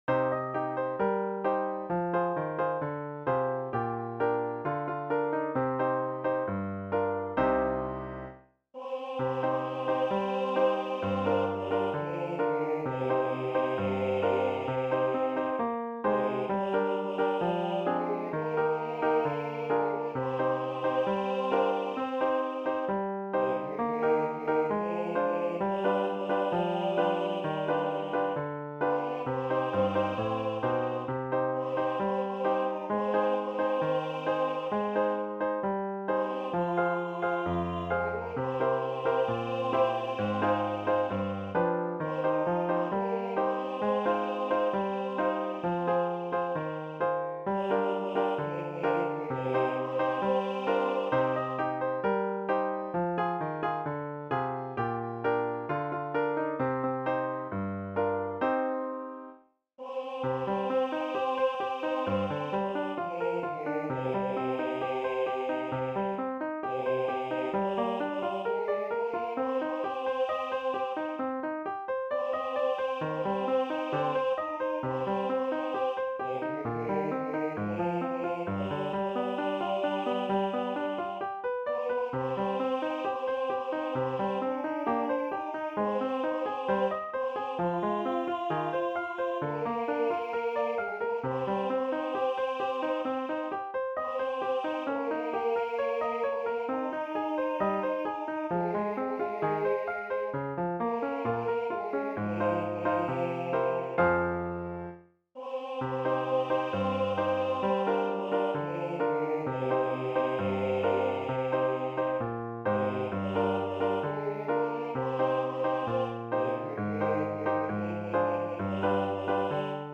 for High Voice and Piano